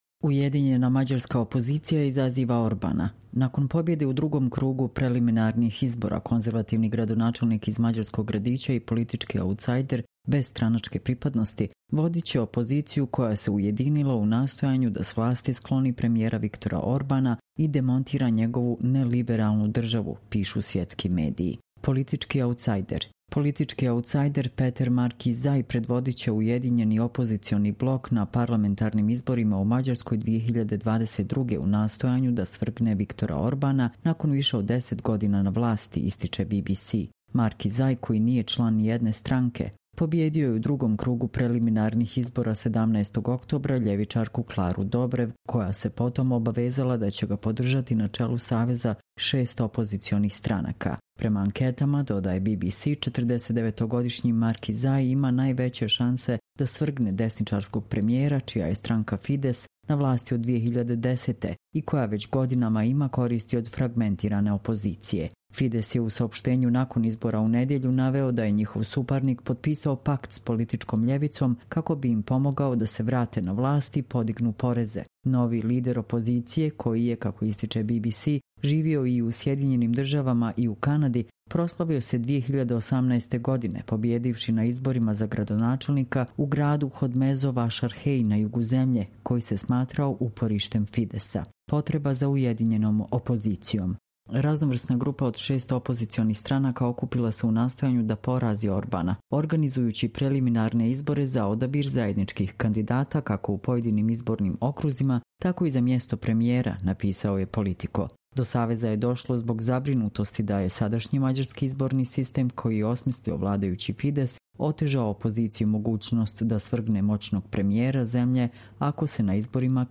Čitamo vam: Ujedinjena mađarska opozicija izaziva Orbana